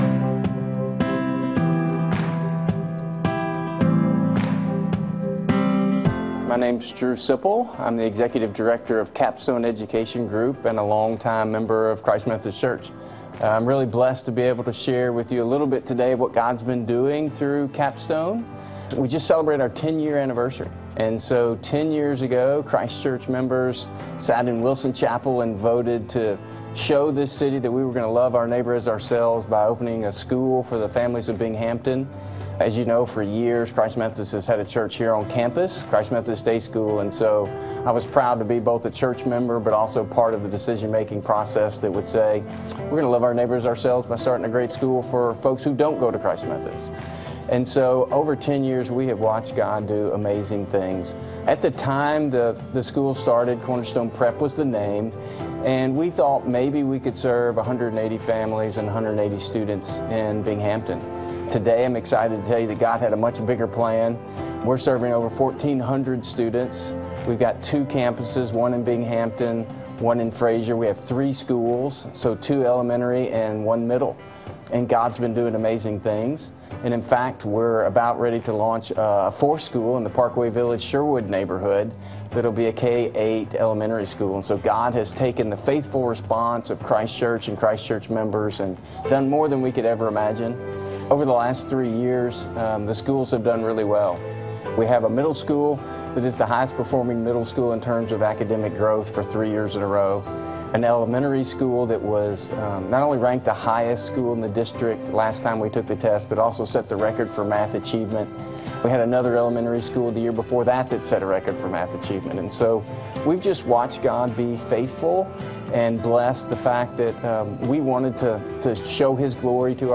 From Series: "Standalone Sermons "